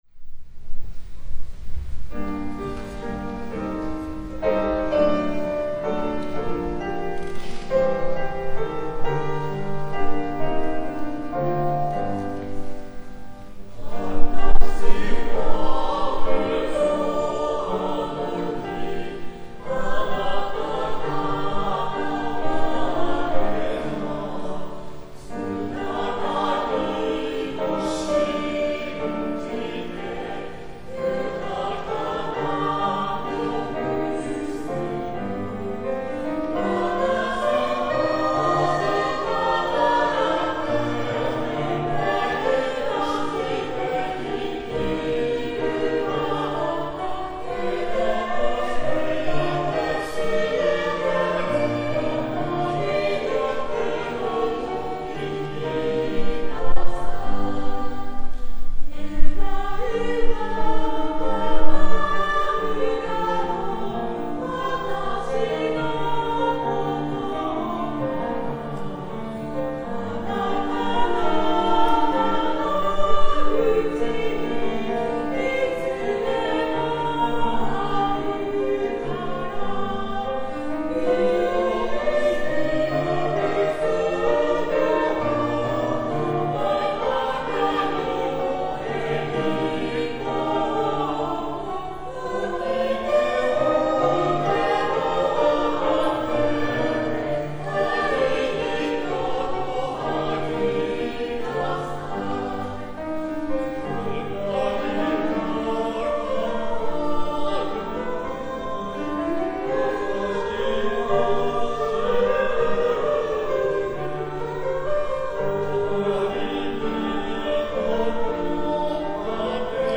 Tonality = g
♪演奏例